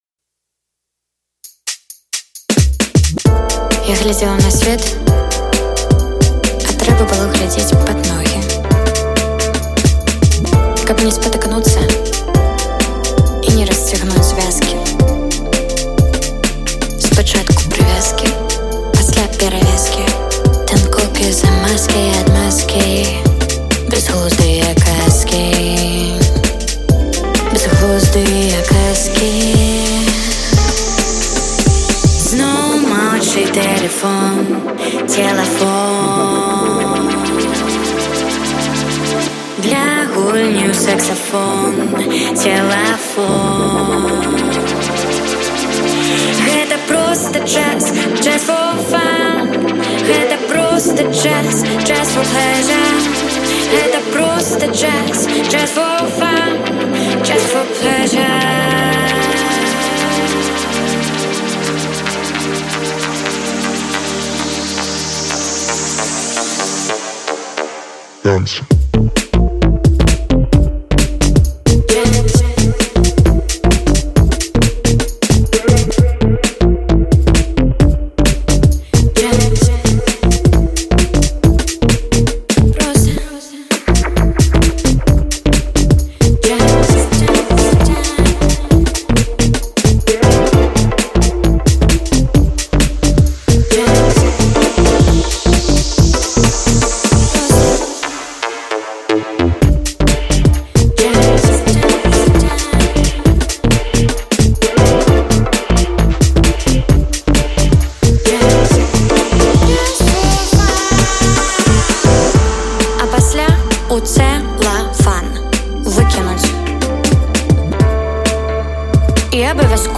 запісаную ў межах студыйнай сэсіі